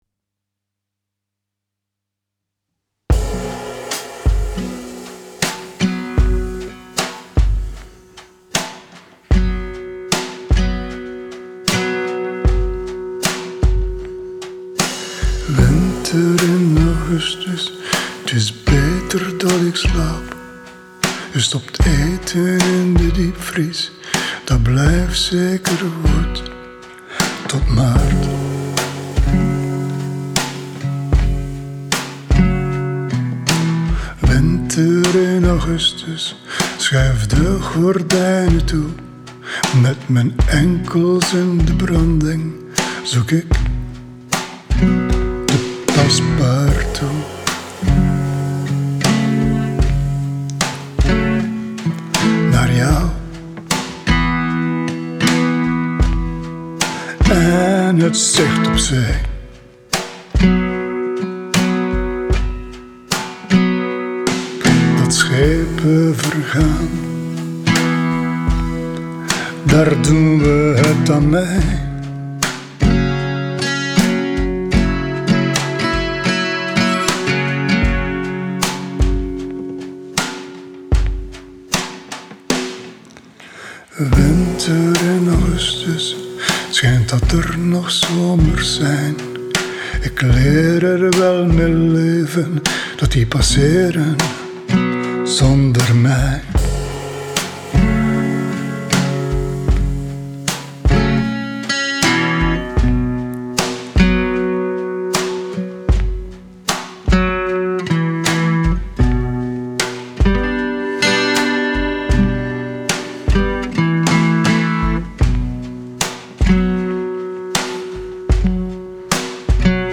Fijn dit te horen Speciale sound met de galmende drums en jouw gitaarspel ! Ook mooie sound met die prachtige hese stem van jou !